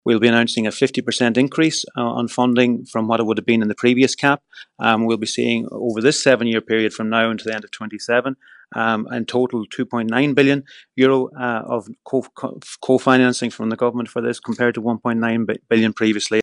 Agriculture Minister, Charlie McConalogue, says the funding will help support farmers through the challenges ahead: